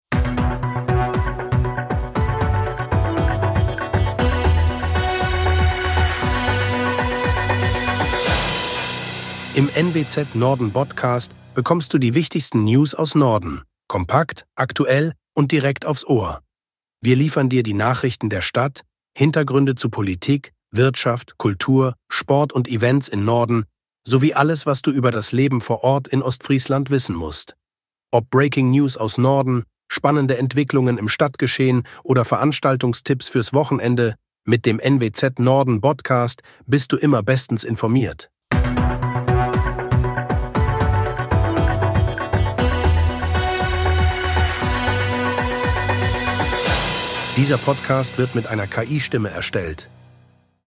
Nachrichten
Dieser Podcast wird mit einer KI-Stimme